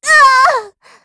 Estelle-Vox_Damage_kr_06.wav